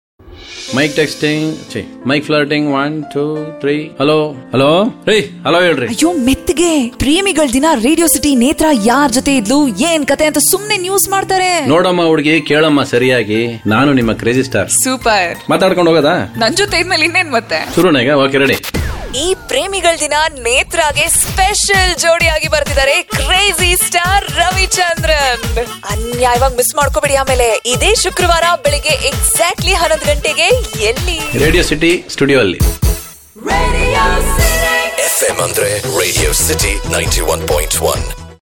Here's a short promo for you!